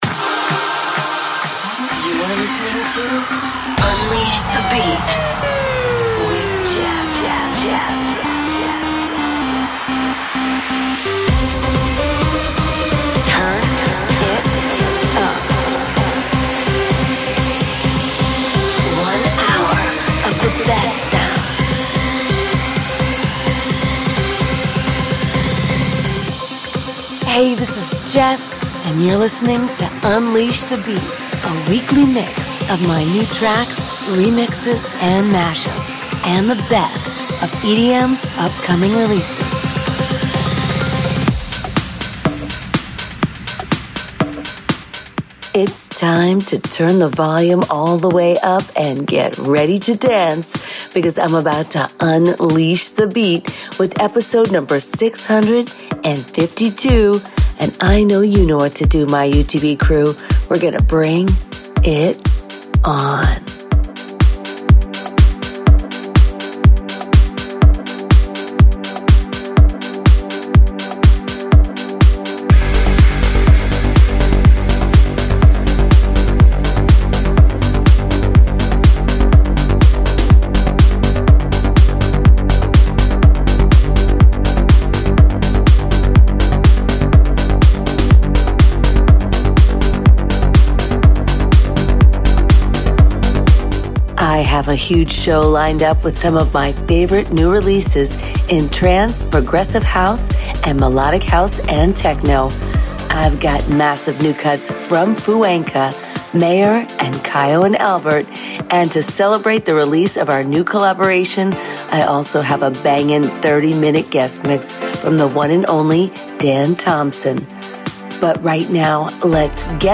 Trance-Melodic House-Techno